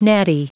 Prononciation du mot natty en anglais (fichier audio)
Prononciation du mot : natty